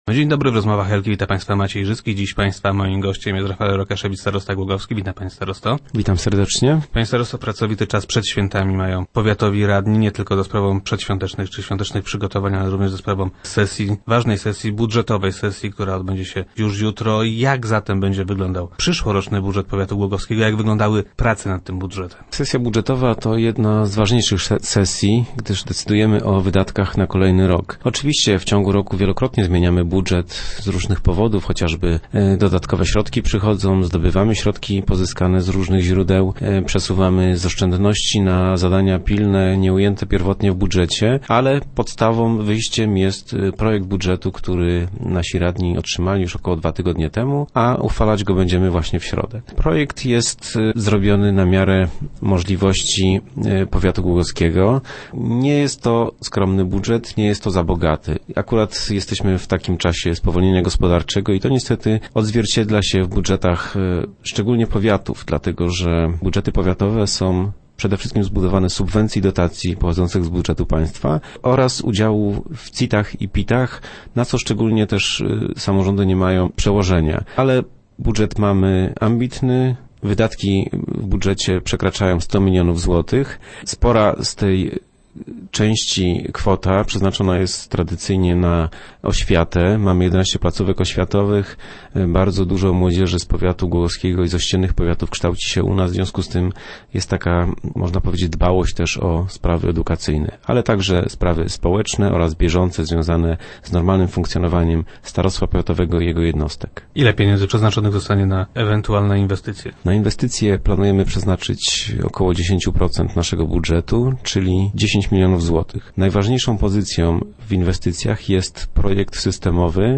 Jak twierdzi starosta, nie będzie on ani szczególnie skromny, ani też zbyt bogaty. Rafael Rokaszewicz był gościem wtorkowych Rozmów Elki.